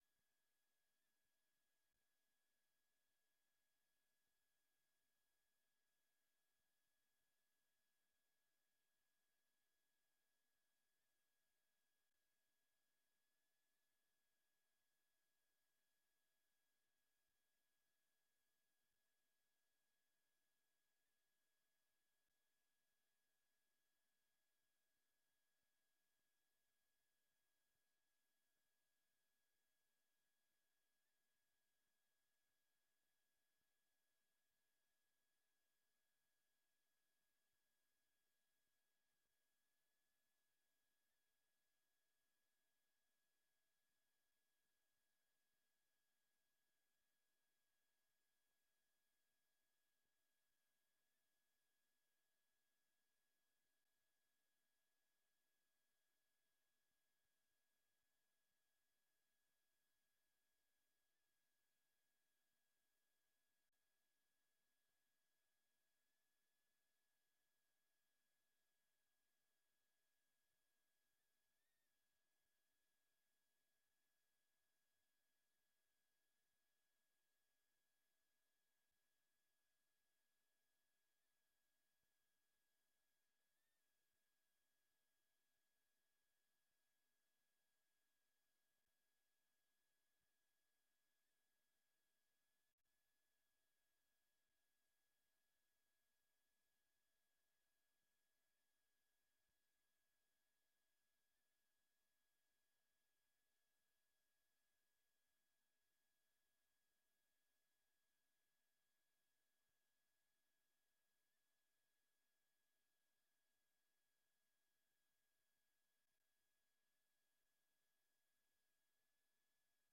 Raadsvergadering 24 november 2022 19:30:00, Gemeente Dronten
Locatie: Raadzaal